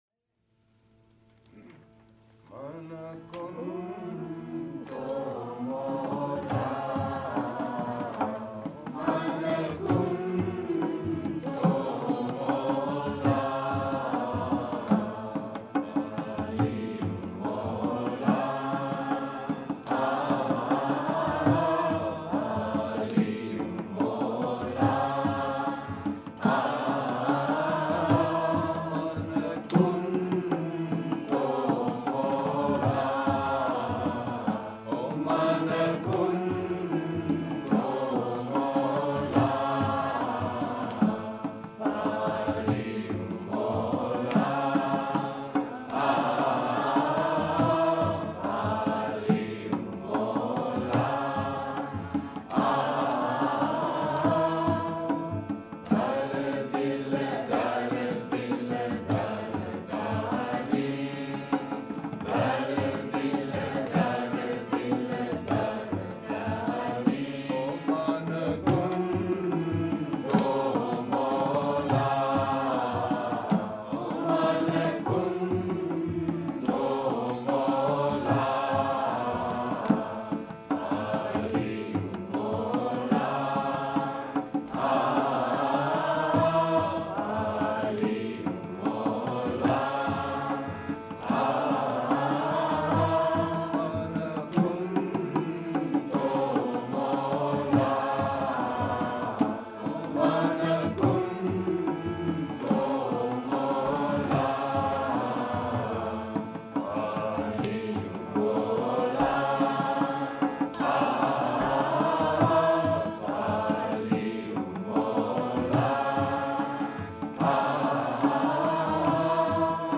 Music from The Universel in katwijk (October 2-3 2004)